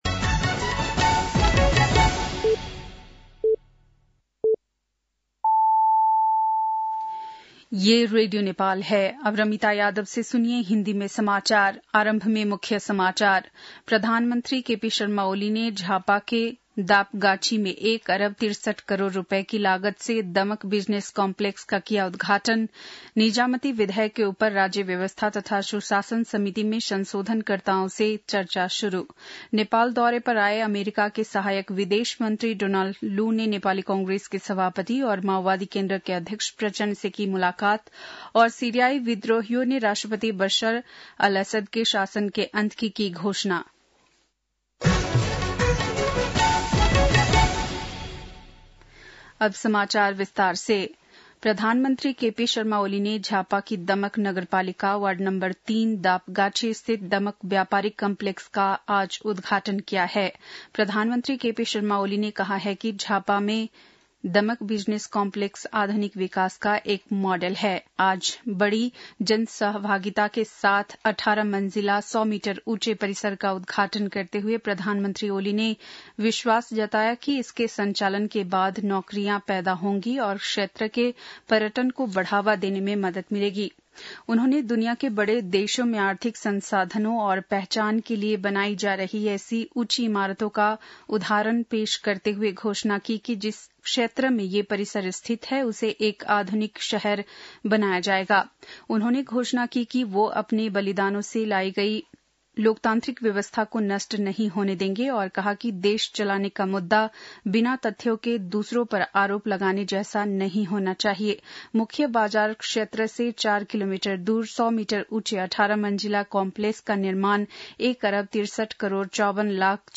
बेलुकी १० बजेको हिन्दी समाचार : २४ मंसिर , २०८१
10-pm-hindi-news-8-23.mp3